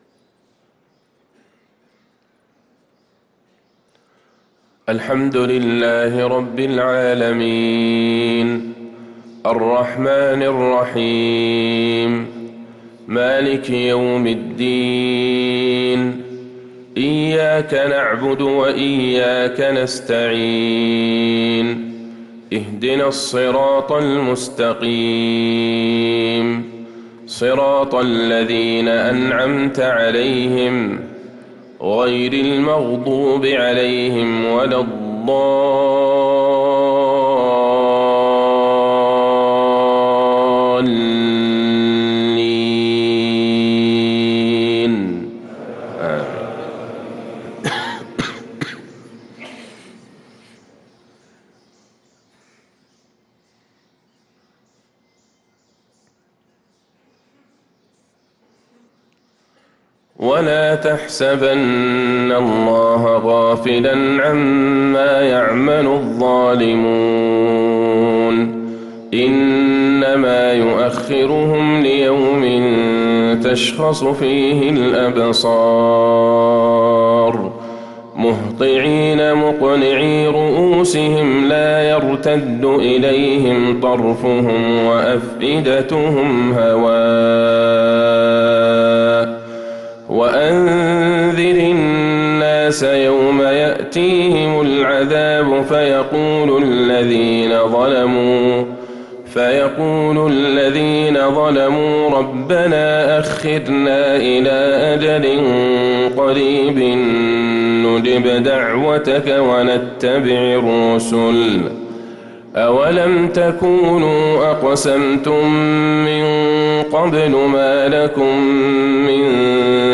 صلاة الفجر للقارئ عبدالله البعيجان 14 رجب 1445 هـ